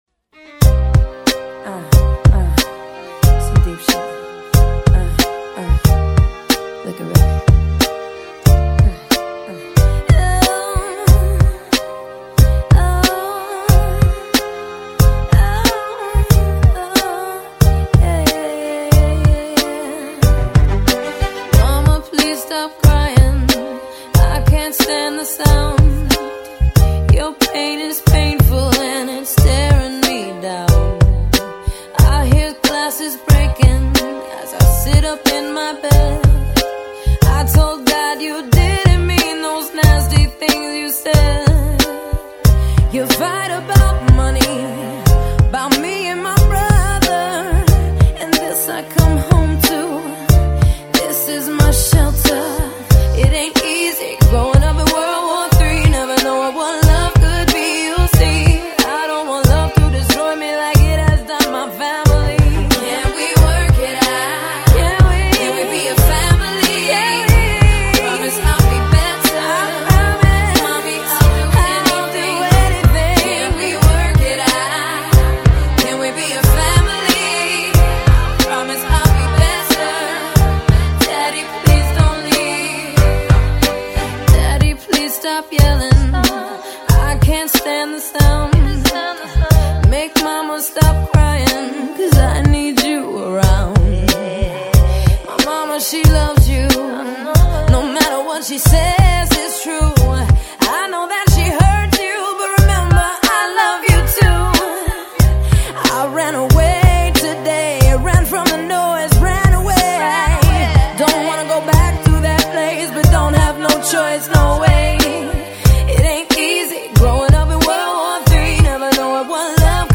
PopRock / AlternativePop / R&B